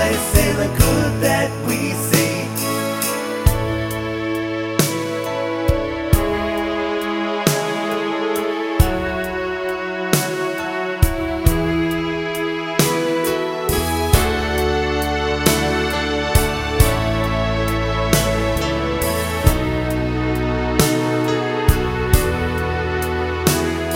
no Backing Vocals Pop (1960s) 3:53 Buy £1.50